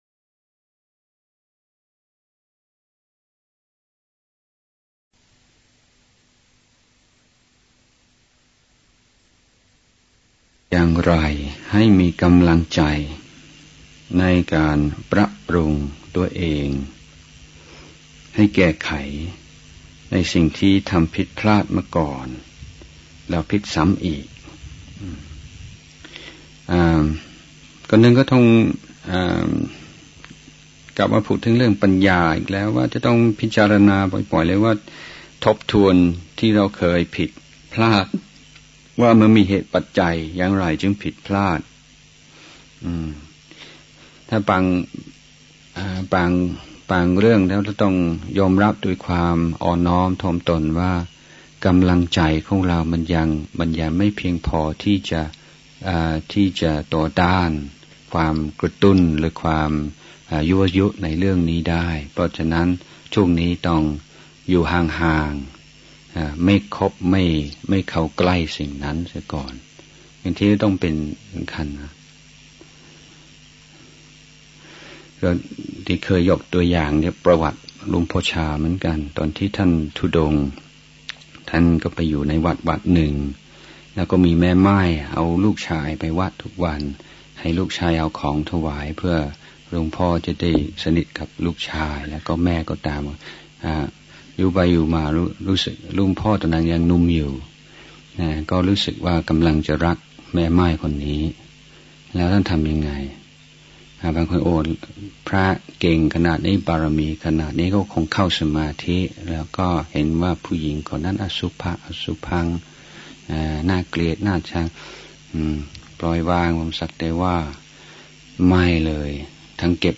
พระธรรมเทศนาปี พ.ศ.2547 ถาม-ตอบ 11-พระอาจารย์ชยสาโร ภิกขุ